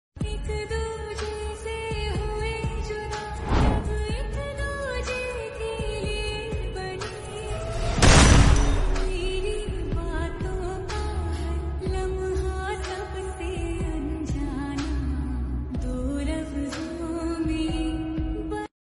Sad Song Edit